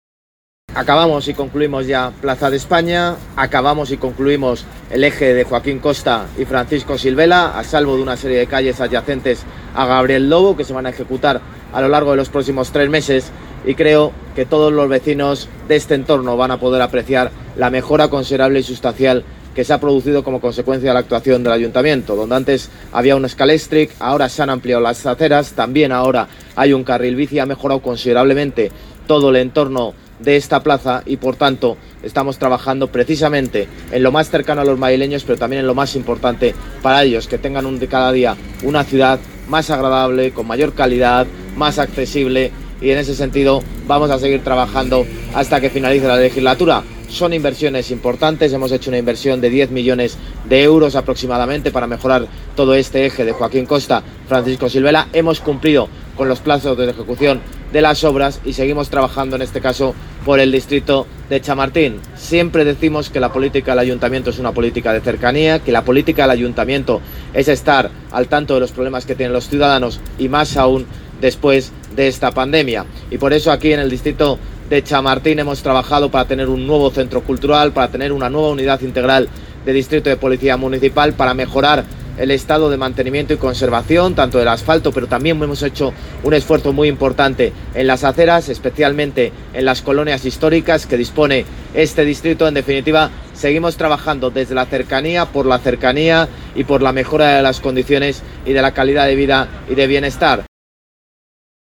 Nueva ventana:Intervención del alcalde, José Luis Martínez-Almeida, durante la visita al eje Francisco Silvela-Joaquín Costa cuyas obras acaban de finalizar